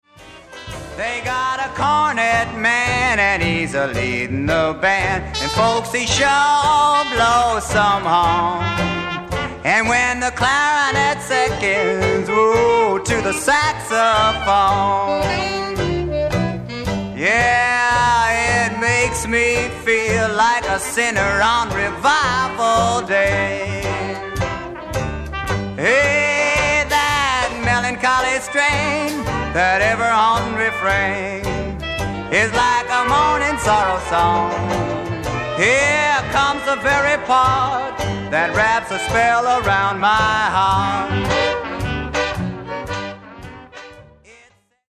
JUG MUSIC / AMERICAN ROOTS MUSIC / BLUES